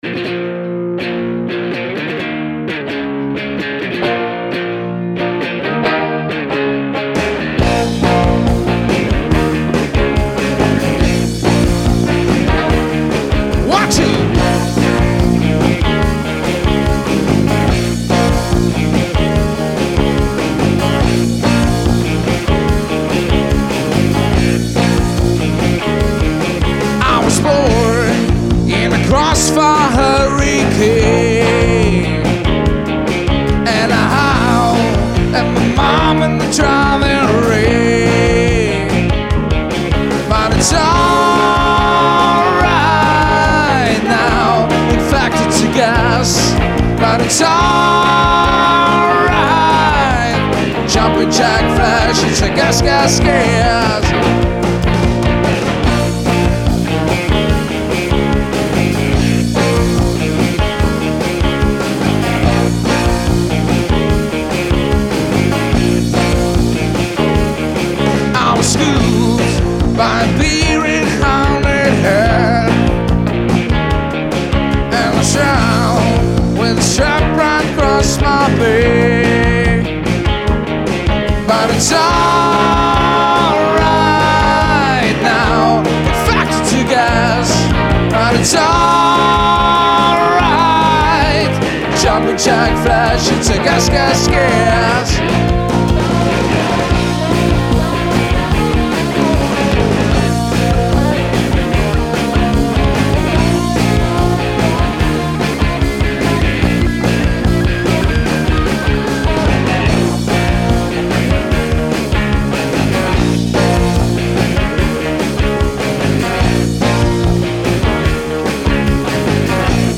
Partyband und Stimmungsband
• Rockband
• Coverband